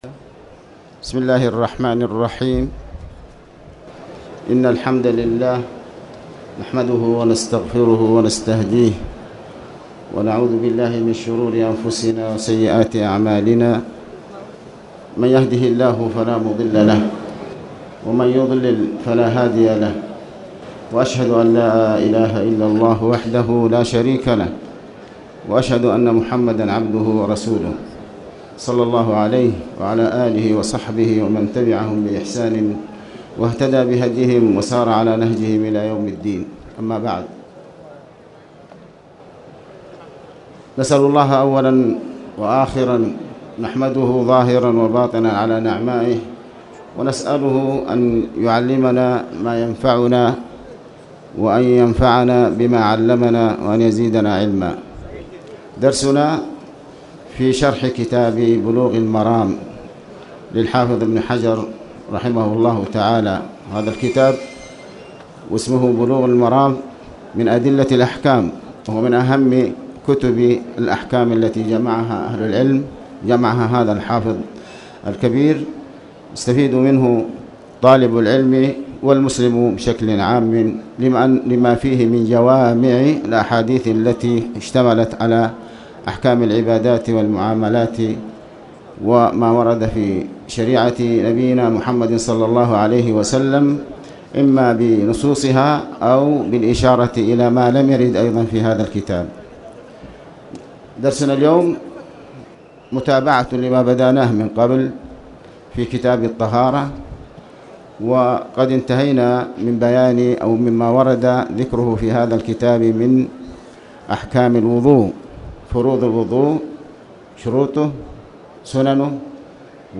تاريخ النشر ١٧ جمادى الآخرة ١٤٣٨ هـ المكان: المسجد الحرام الشيخ